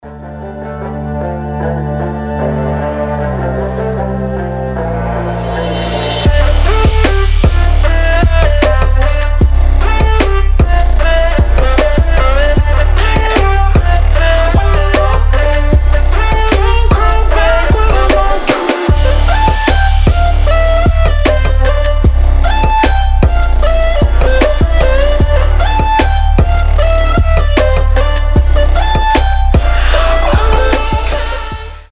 • Качество: 320, Stereo
восточные мотивы
мелодичные
Electronic
Trap
future bass
Мелодичная трэп-музыка